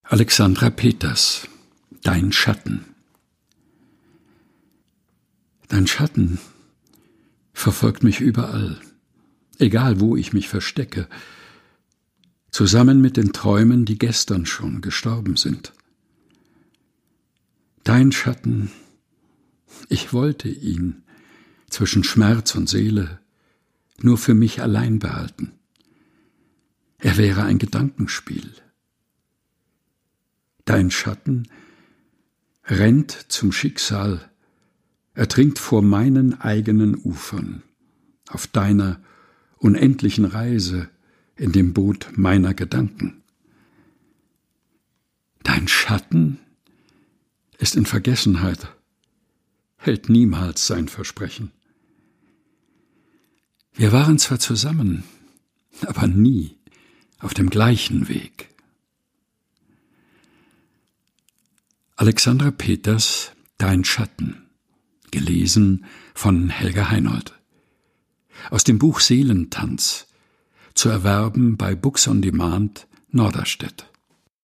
Texte zum Mutmachen und Nachdenken - vorgelesen
im heimischen Studio vorgelesen